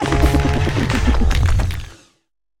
Cri de Vrombotor dans Pokémon HOME.